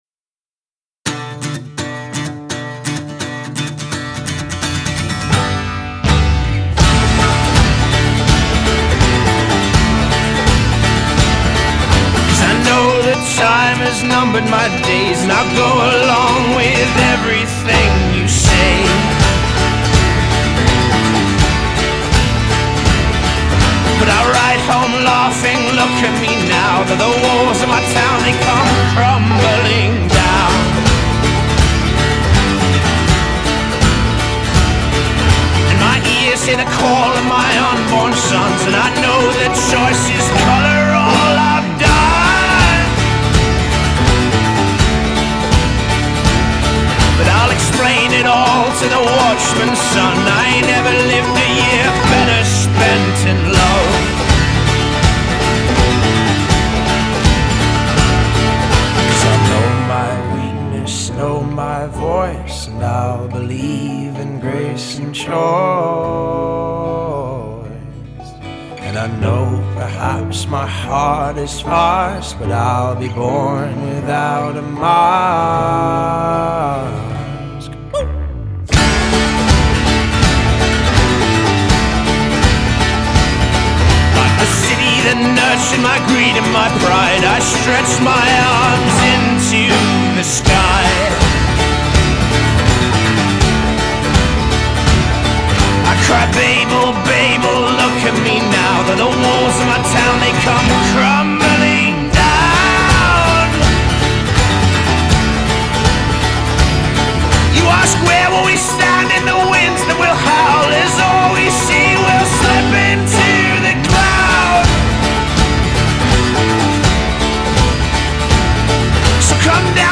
Style: Folk Rock